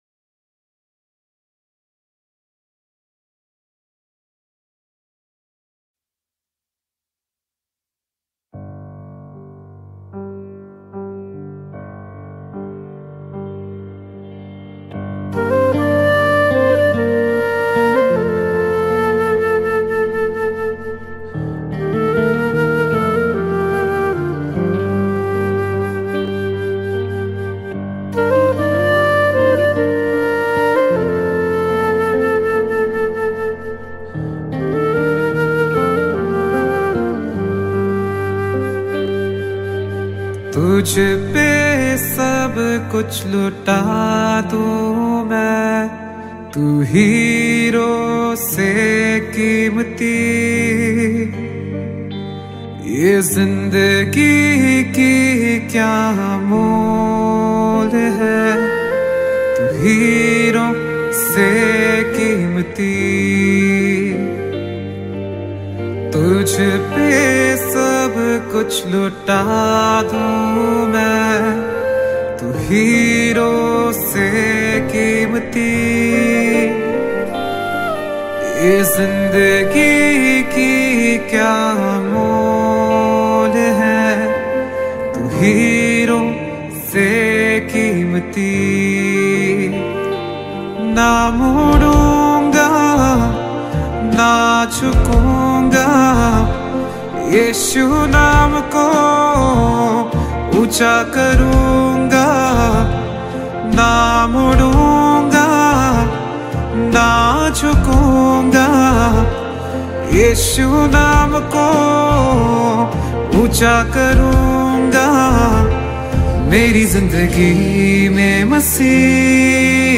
Posted in Christian Song Tagged Hindi Christian Song